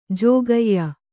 ♪ jōgayya